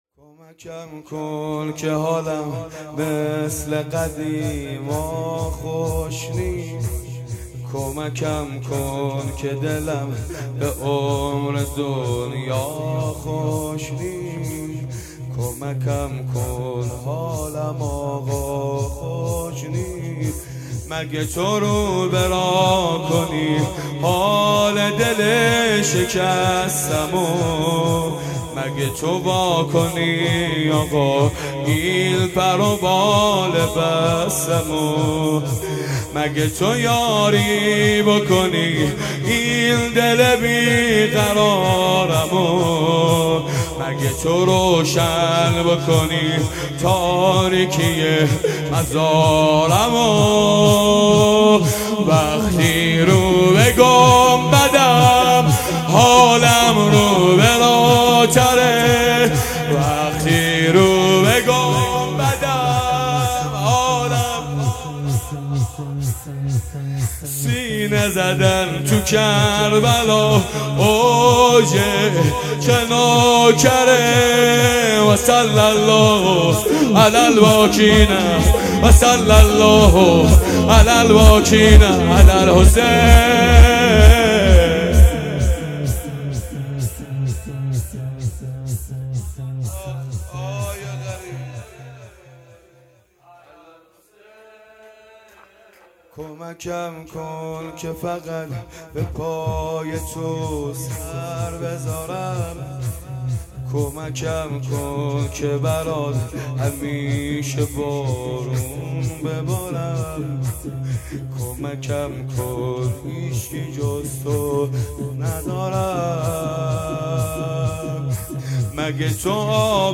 شور 6
آخرین شب جمعه سال 25 اسفند 1401